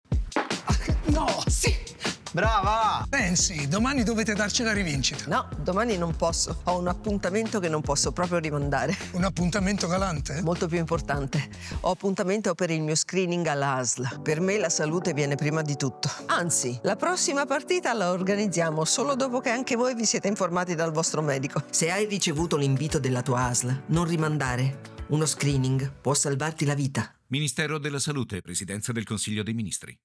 Lo spot radio
Protagonista e volto del messaggio è l’attrice Nancy Brilli, che, con la sua sensibilità, invita ogni cittadino a non ignorare un’opportunità fondamentale: aderire ai percorsi di prevenzione offerti gratuitamente dal Servizio Sanitario Nazionale.